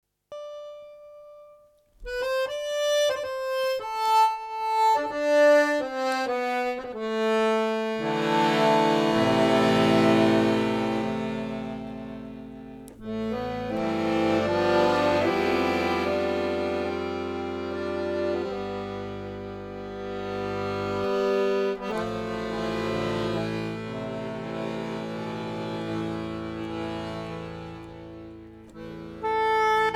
saxophonist